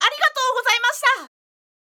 ダウンロード 女性_「ありがとうございました」
パワフル挨拶